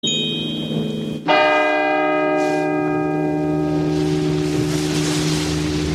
• CHIMES CRYSTAL BELL TOLL.wav
CHIMES_CRYSTAL_BELL_TOLL_mQc.wav